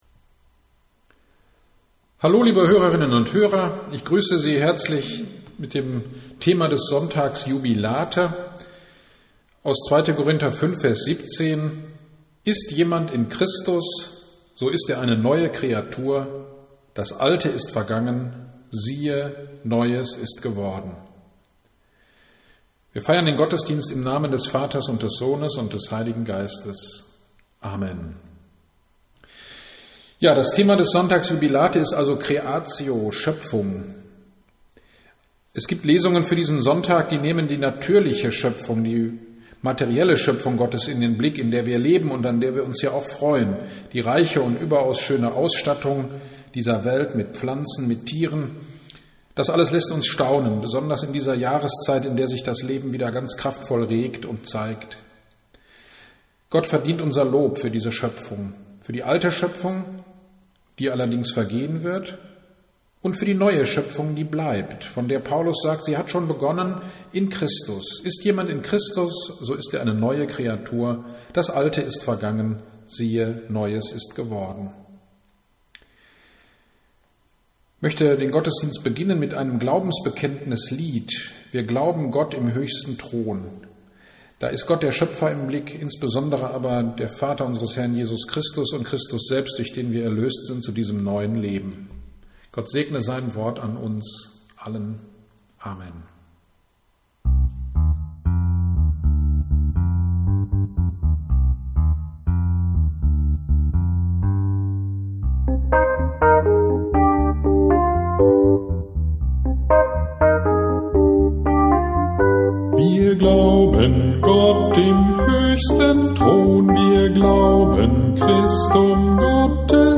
GD am 25.04.21 (Jubilate) Predigt zu 2. Korinther 5,17 - Kirchgemeinde Pölzig